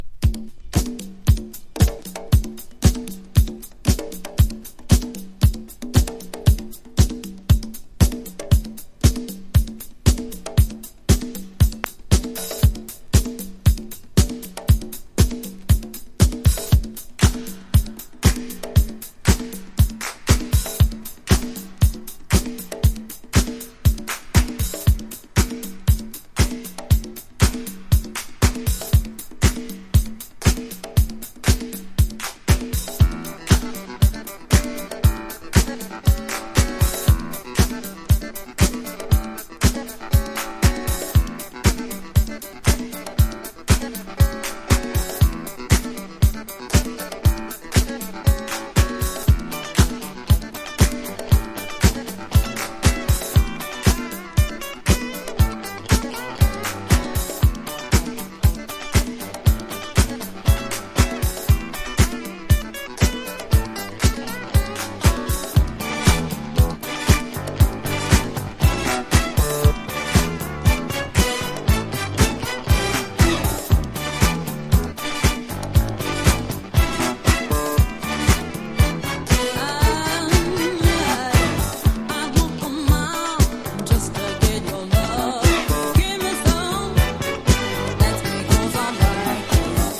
FUNK / DEEP FUNK# SOUL 45# DISCO# LOFT / GARAGE